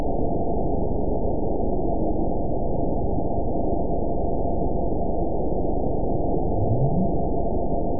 event 916876 date 02/12/23 time 00:47:14 GMT (2 years, 8 months ago) score 9.60 location TSS-AB03 detected by nrw target species NRW annotations +NRW Spectrogram: Frequency (kHz) vs. Time (s) audio not available .wav